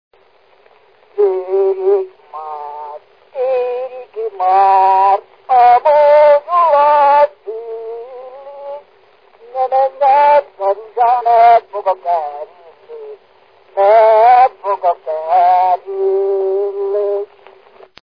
Dunántúl - Tolna vm. - Felsőireg
Stílus: 8. Újszerű kisambitusú dallamok
Kadencia: V 1 (4) V 1